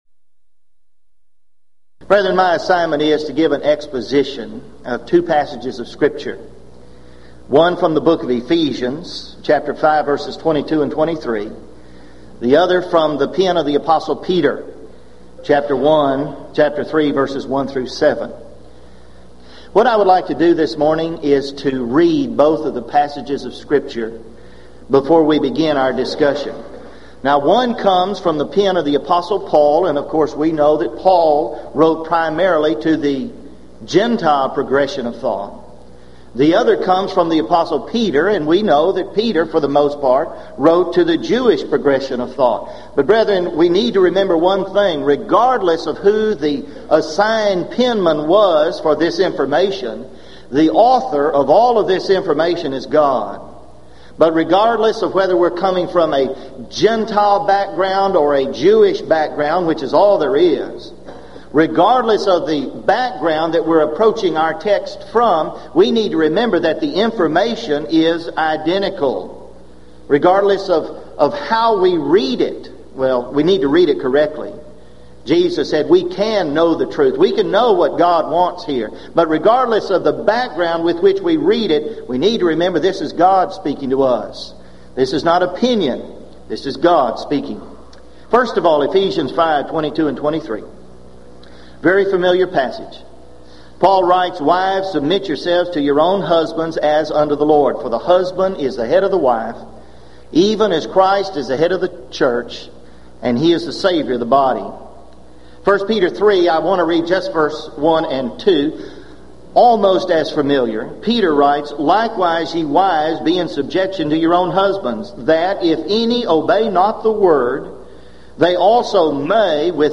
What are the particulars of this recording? Event: 1996 Gulf Coast Lectures Theme/Title: Lively Issues On The Home And The Church